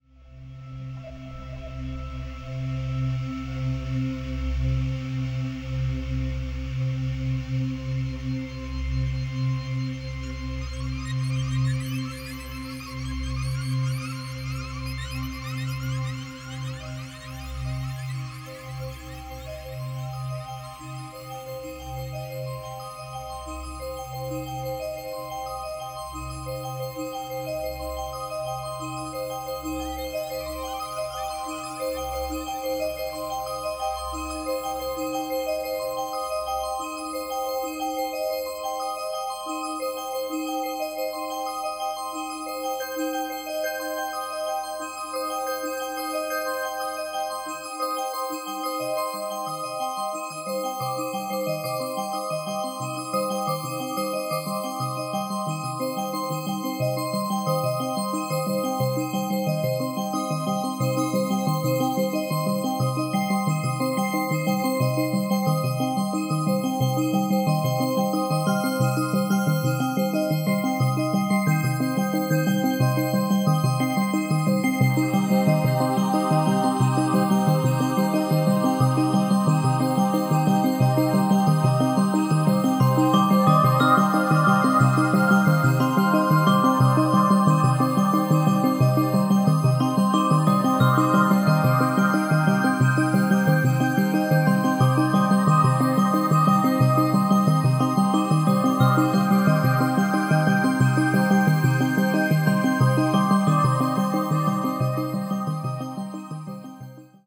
harmonic
spacey
dreamy
sequencer-based
relaxing
Music to dream to.